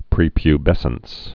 (prēpy-bĕsəns)